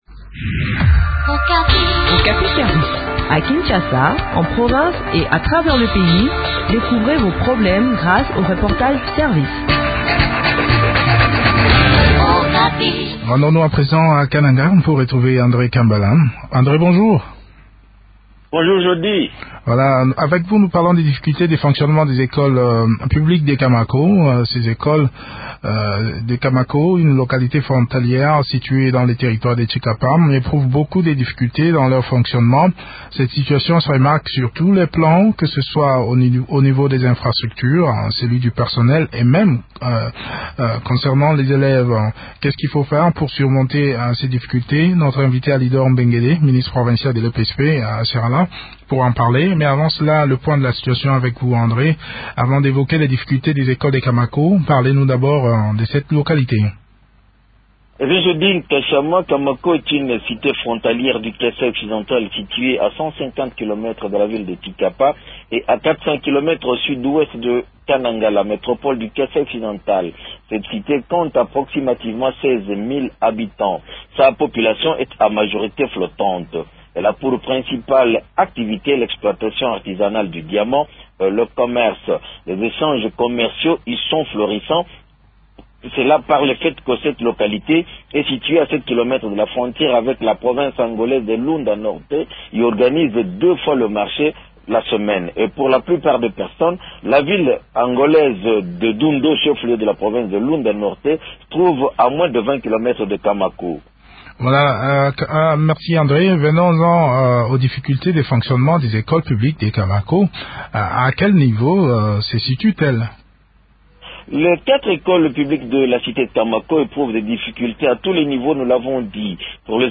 Les écoles publiques de Kamako sont butées à de sérieuses difficultés de fonctionnement. Des élèves suivent les enseignements sous les arbres, les enseignants ne sont pas mécanisés et il y a tant d’autres problèmes. Le point de la situation dans cet entretien